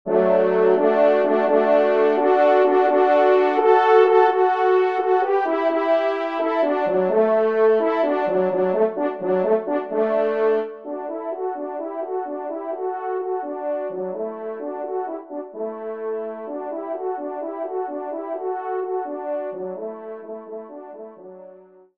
TROMPE 2 en Exergue